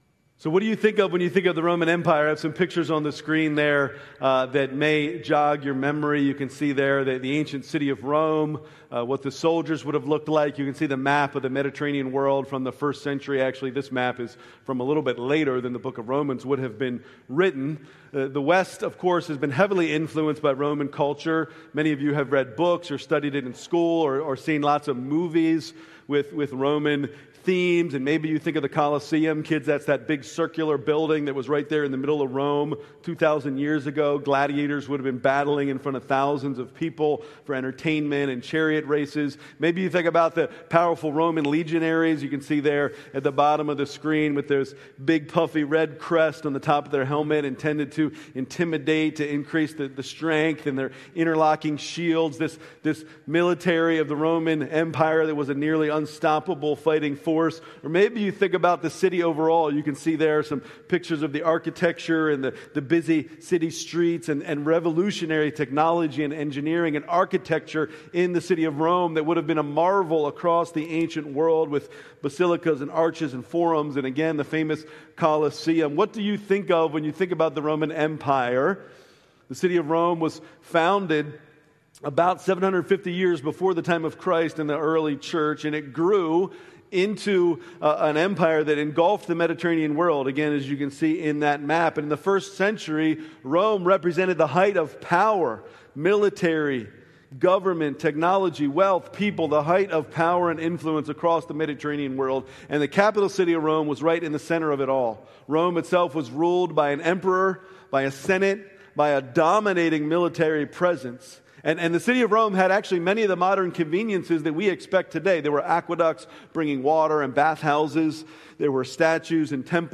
August 31, 2025 Sunday Worship Service at Living Hope Church